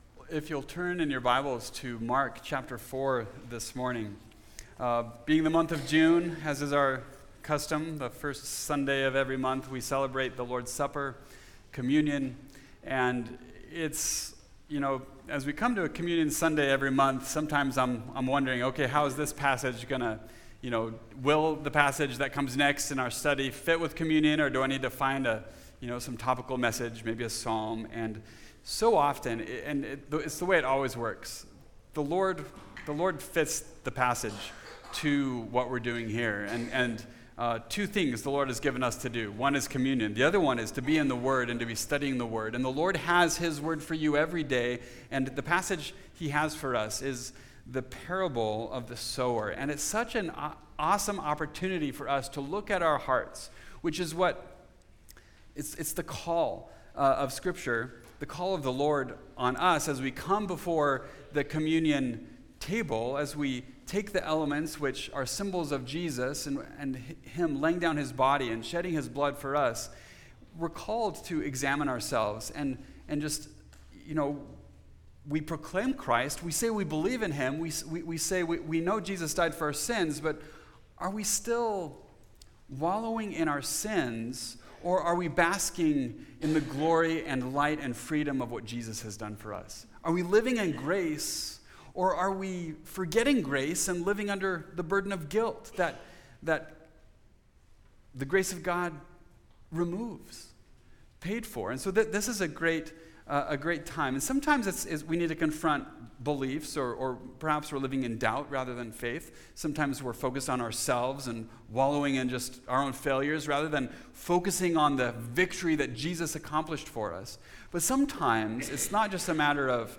Listen and Look (Mark 4:1-20) – Mountain View Baptist Church